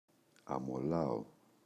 αμολάω [amoꞋlao]
αμολάω.mp3